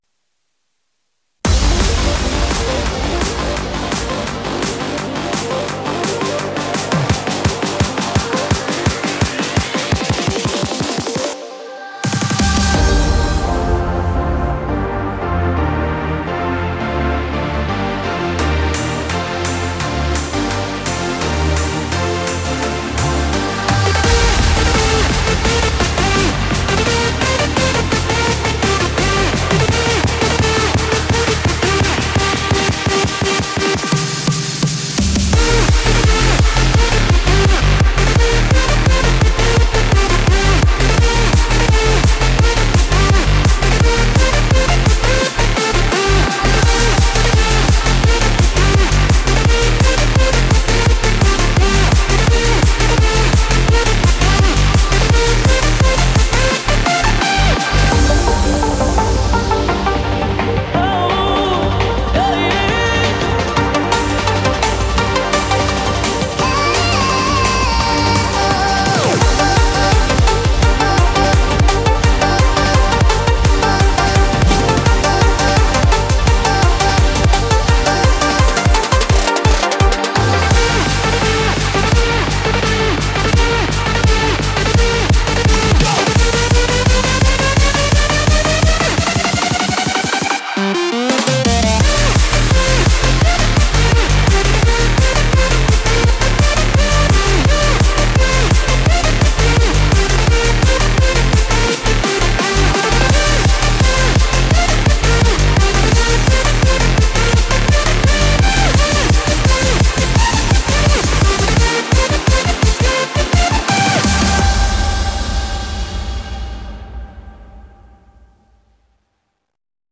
BPM170
Audio QualityPerfect (Low Quality)